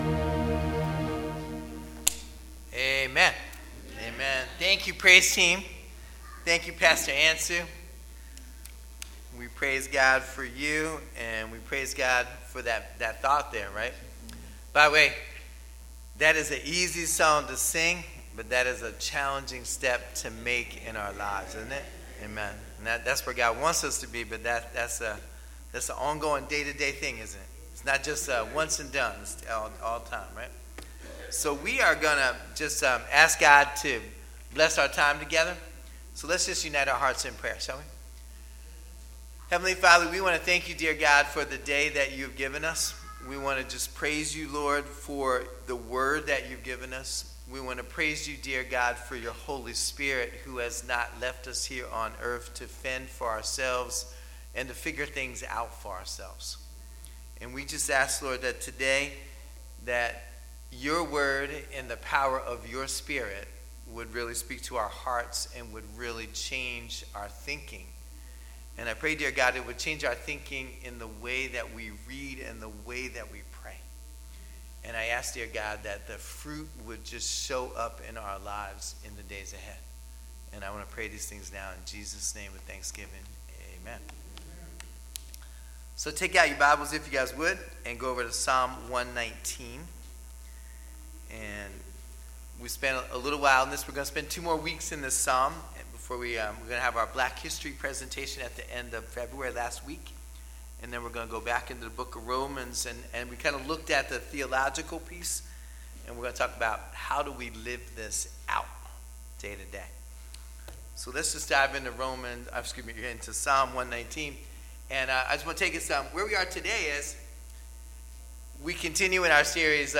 Sermons | First Baptist Church of Willingboro, NJ